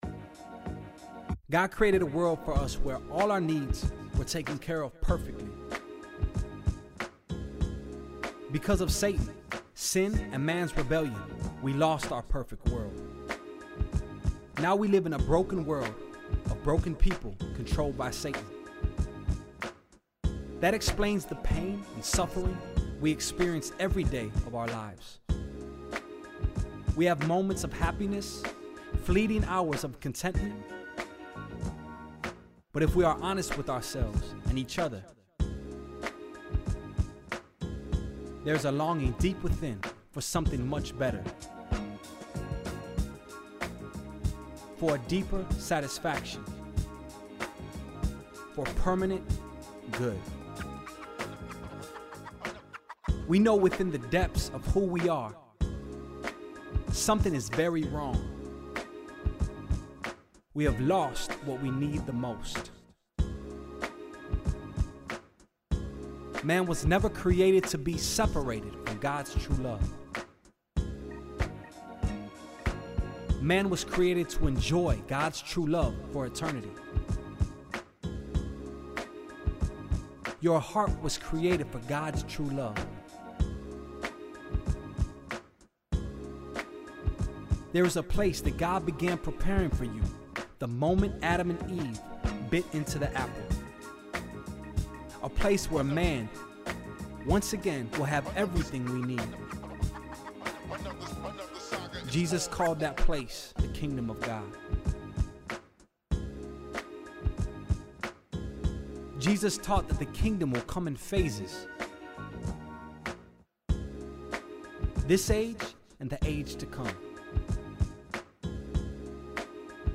Hope Speaks ESL Audio Lesson 21 Age Teen, Teen-Lower, Teen-Upper, Adult Program Hope Speaks ESL Type Audio Region Global Country Language English Download Play Created by OneHope, the Hope Speaks ESL Audio includes 23 files that feature Scripture passages that directly correspond to the Topic of each lesson in the Teacher’s Guide and Student Workbook.